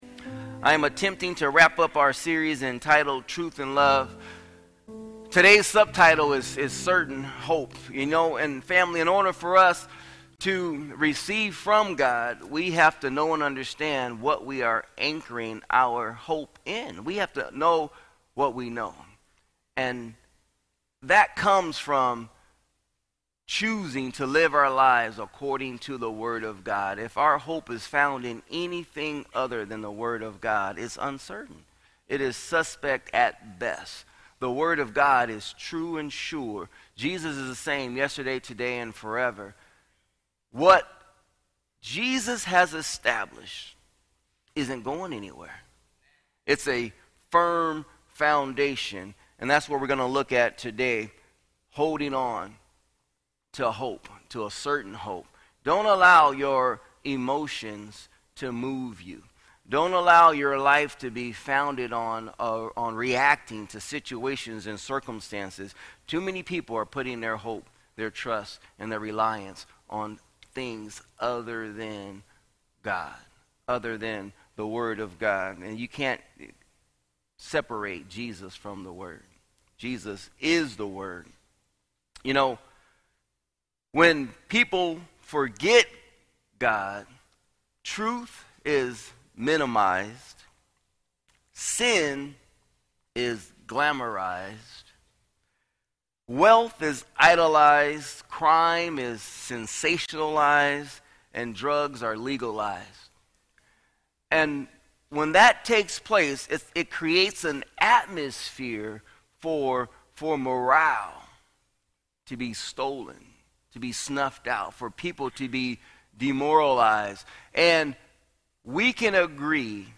Sermons | Word Life Church